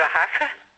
ar Ha-fe
cafe--le---ar-c-hafe--S.mp3